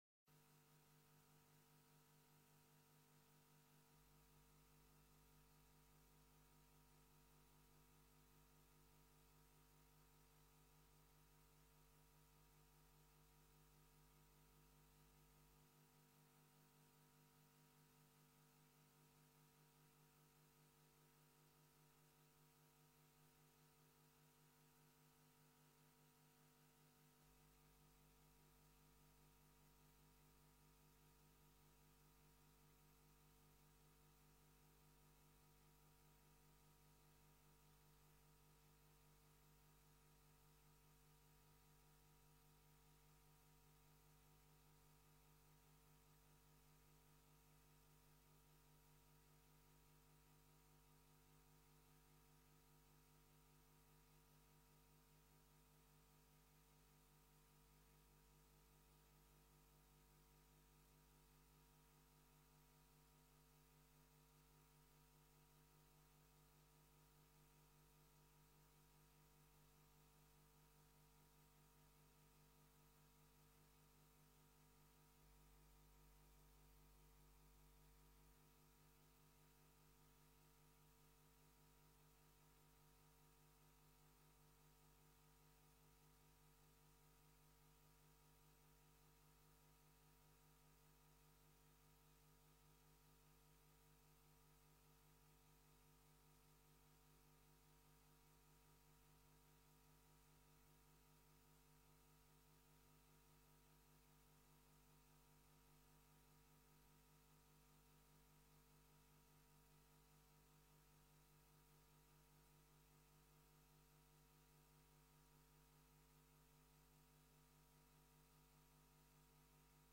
Informatiebijeenkomst 22 juni 2017 20:30:00, Gemeente Tynaarlo
Download de volledige audio van deze vergadering